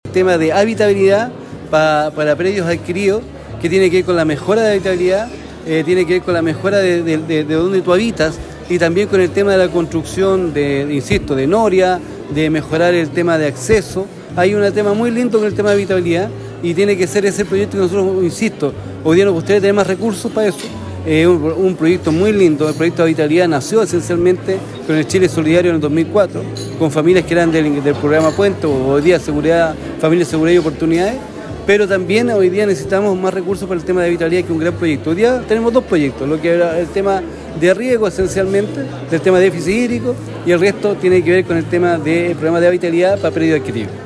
El Seremi de Desarrollo Social también se refirió al concurso público de equipamiento básico y habitabilidad de predios adquiridos y/o transferidos tiene por objetivo mejorar las prácticas productivas, asociadas a la subsistencia y autoconsumo, que tiendan a establecer la seguridad alimentaria de las familias indígenas.